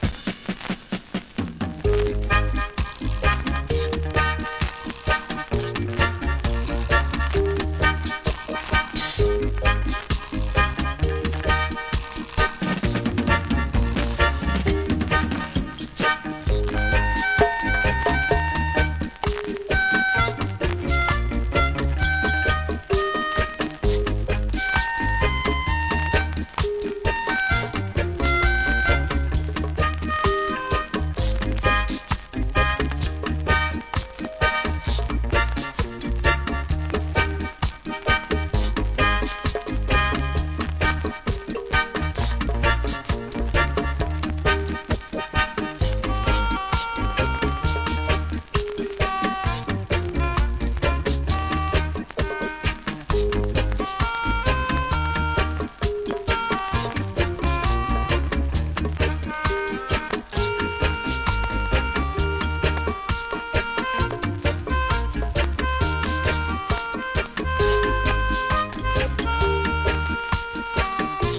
REGGAE
メロディカINST.物!!